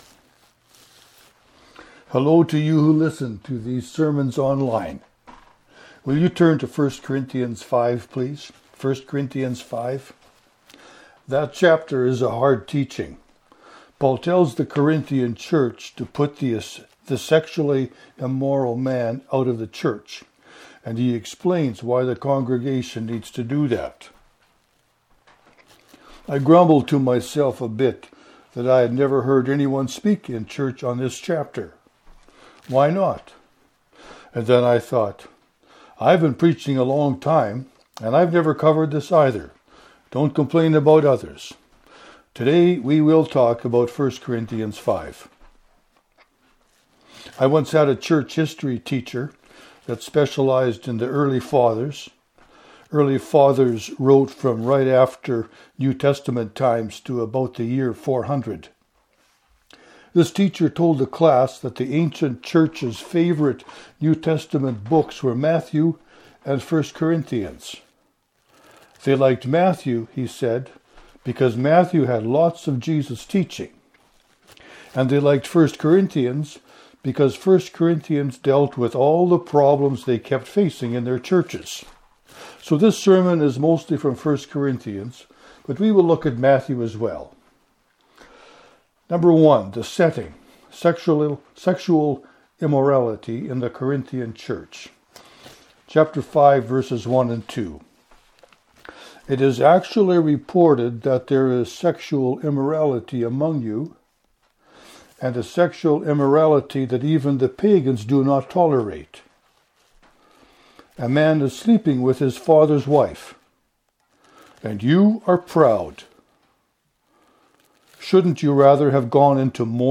This sermon is mostly from 1 Corinthians, but we’ll look at Matthew as well.